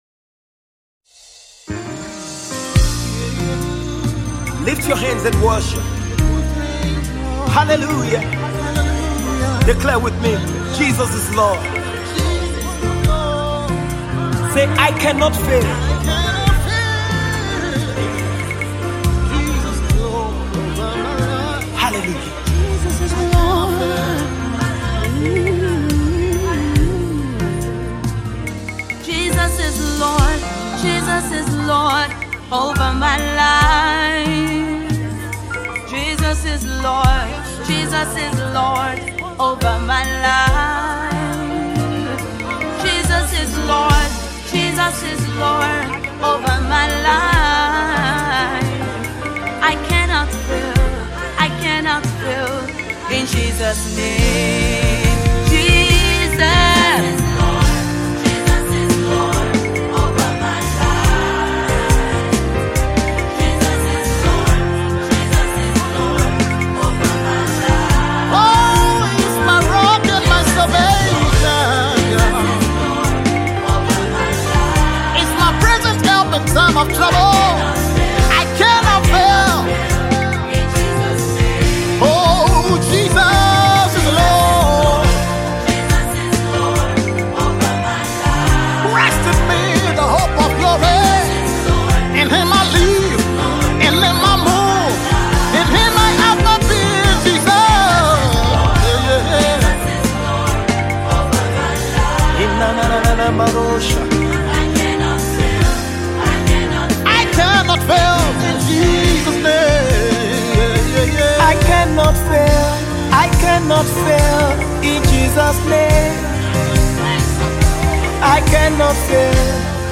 This is more than a song but a prophetic chant